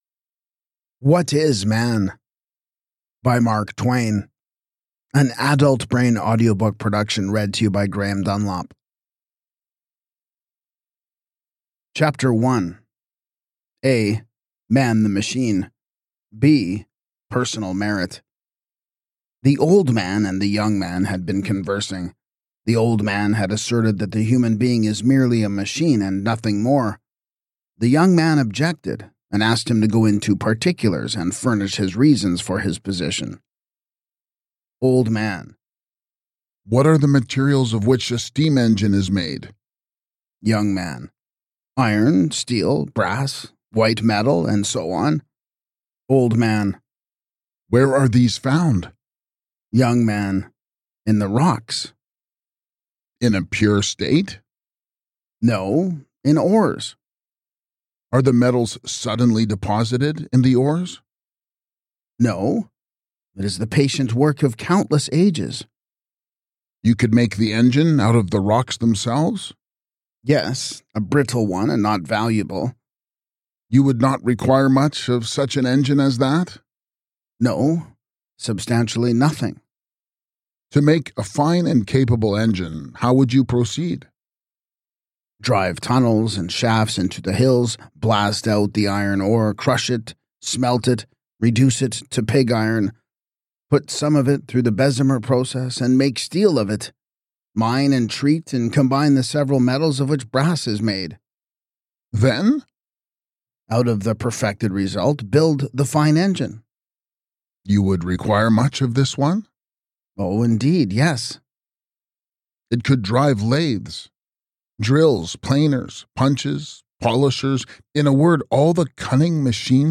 Perfect for fans of classic literature, moral philosophy, and fearless inquiry, this audiobook offers a timeless exploration of what it really means to be human.